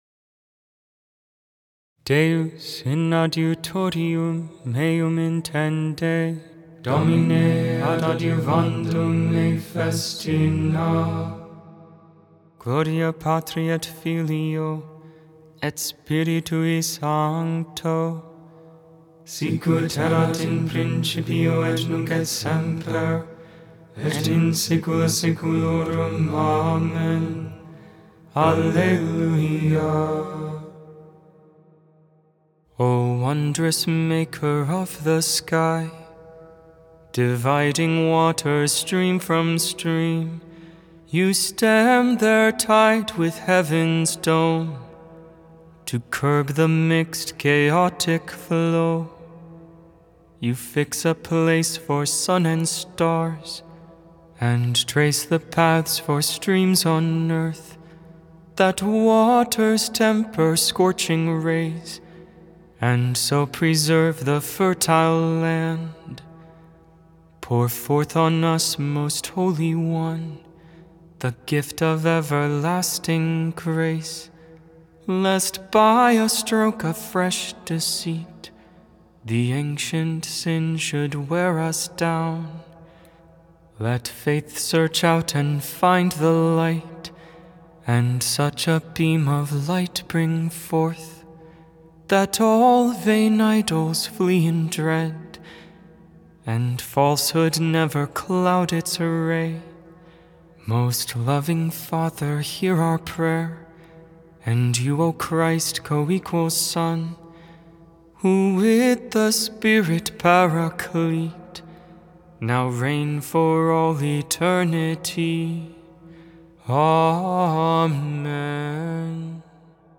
tone 8